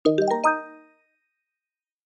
Darmowe dzwonki - kategoria SMS
Dzwonek - Status - Sukces
Standardowy dźwięk dla statusu sukcesu, czy przejścia jakiegoś etapu z powodzeniem.